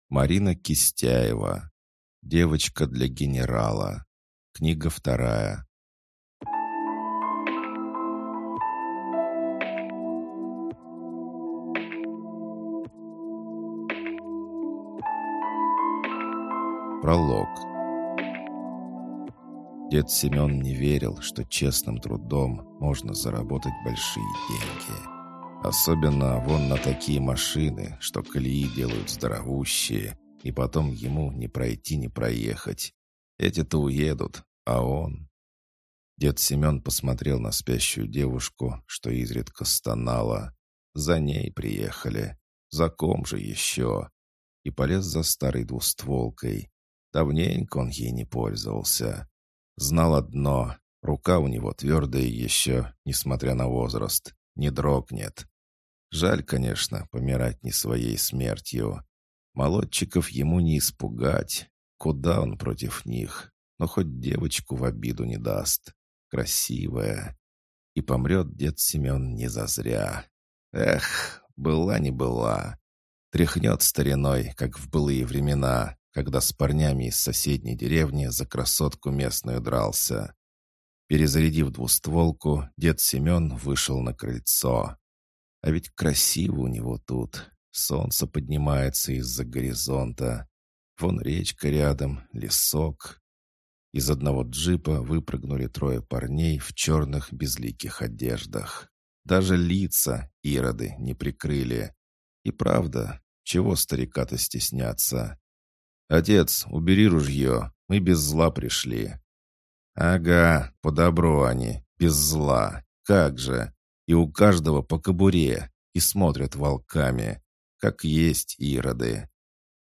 Аудиокнига Девочка для генерала 2 | Библиотека аудиокниг